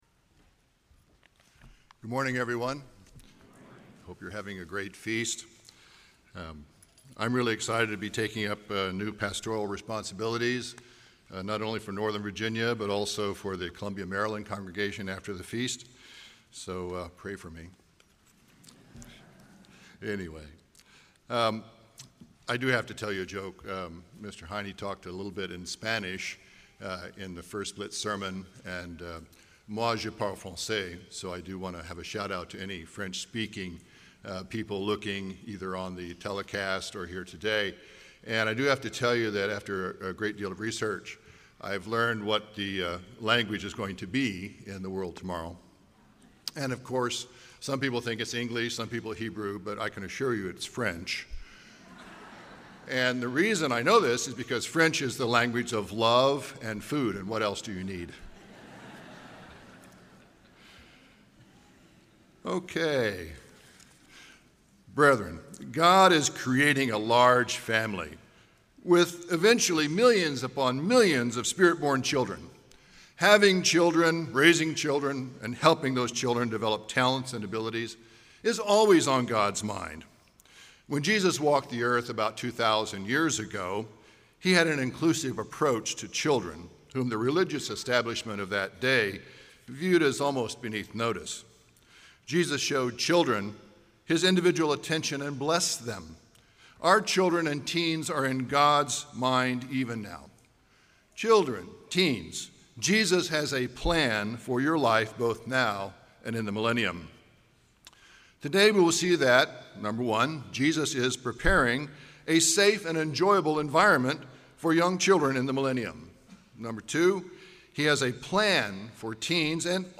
This sermon was given at the Jekyll Island, Georgia 2022 Feast site.